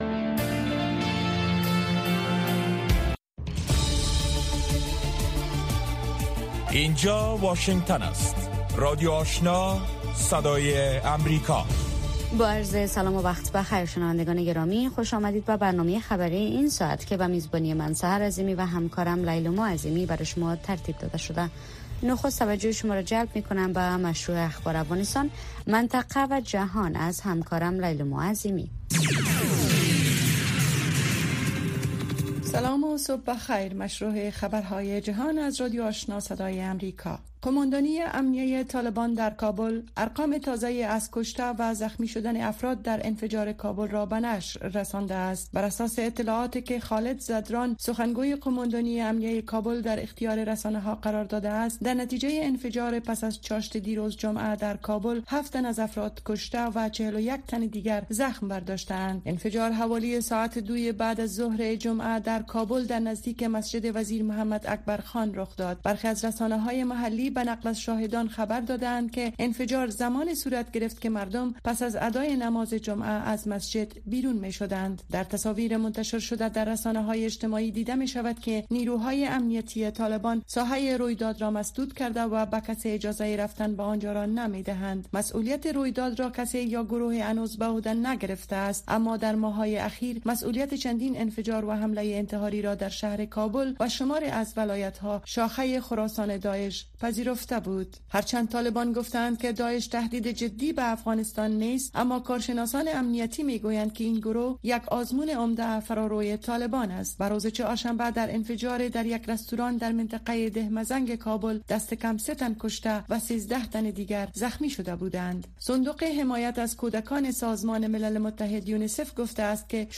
برنامۀ خبری صبحگاهی